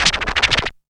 SPEED SCRATH.wav